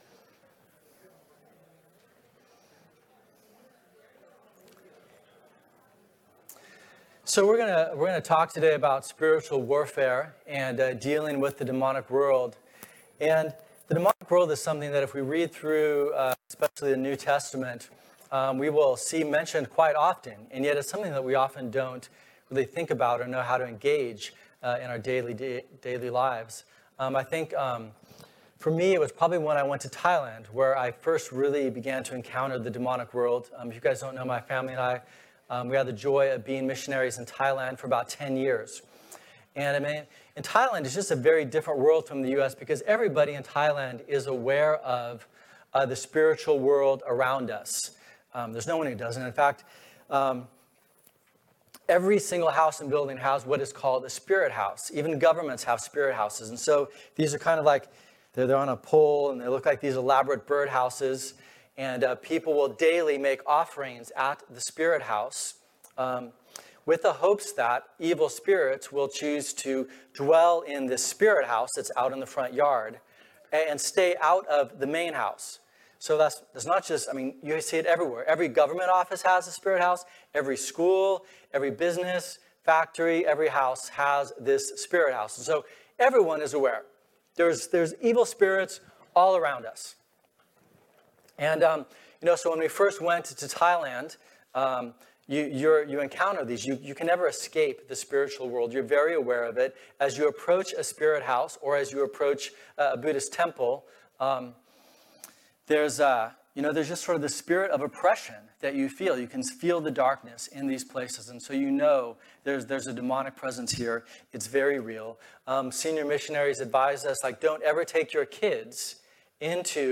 Type: Special Event, Sunday School